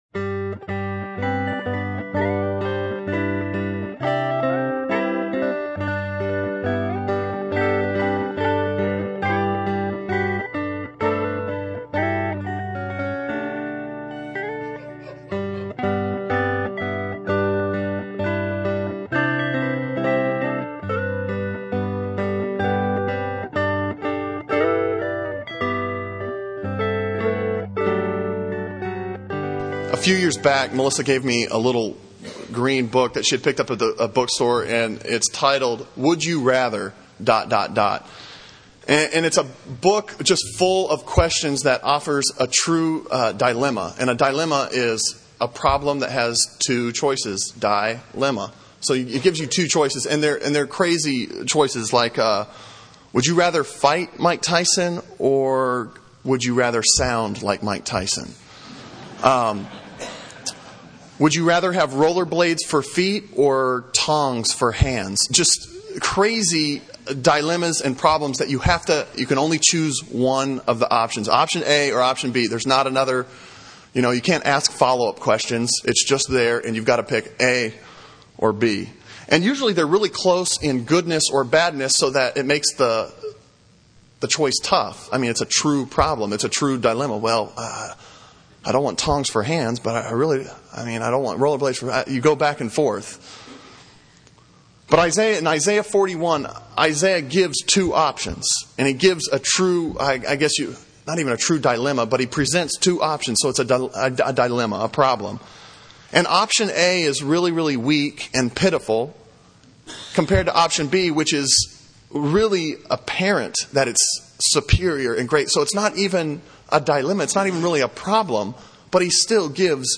Sermon on Isaiah 41:1-20 from February 18